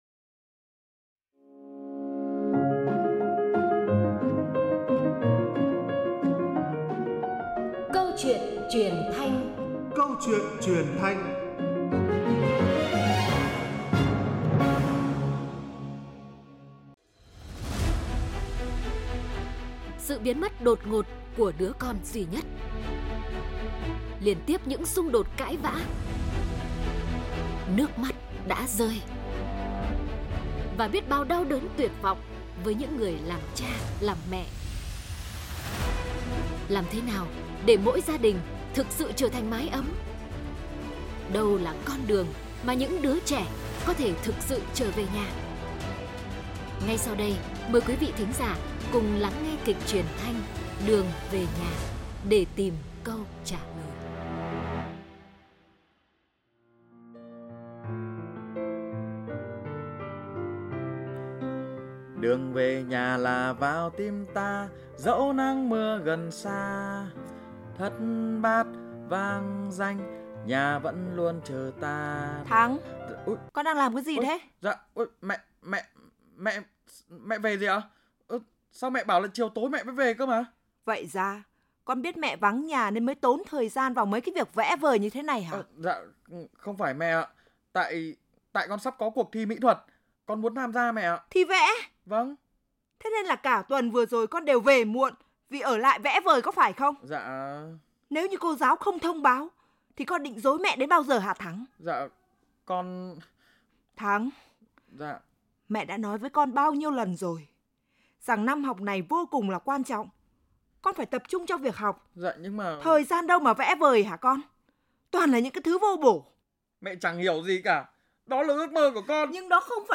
10672_THANHHOA_CAUCHUYENTRUYENTHANH_Duong_ve_nha.mp3